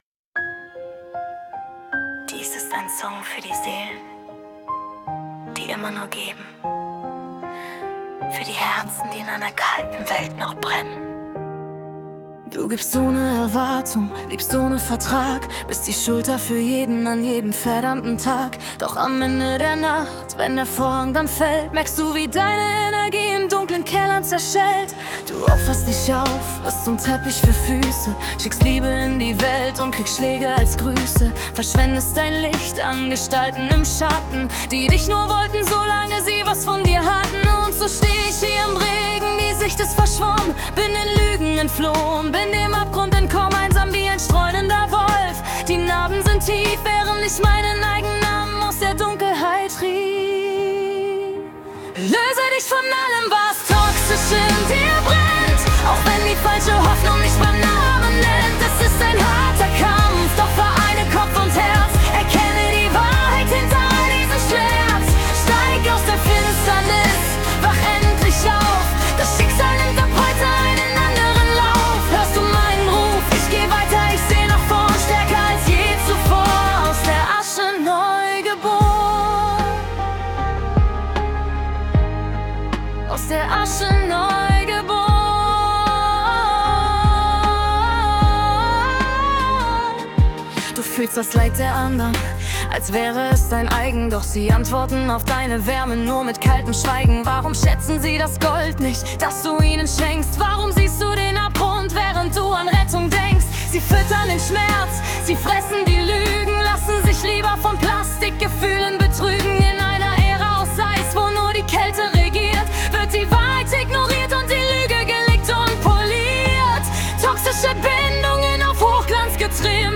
Pop, Romatic, Epic, FemaleVoice, Alternative